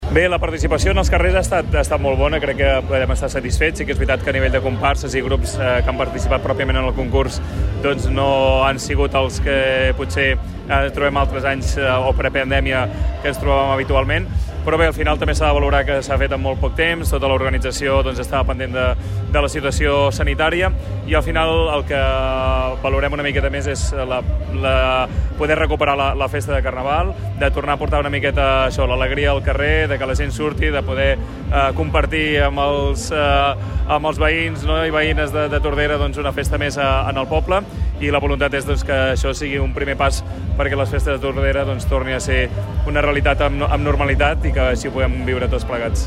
El regidor de festes, Pau Megias, fa un balanç de la celebració.